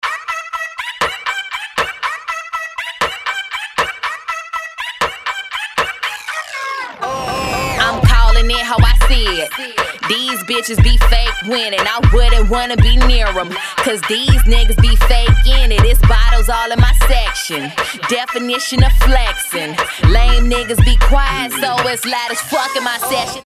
• Качество: 320, Stereo
OST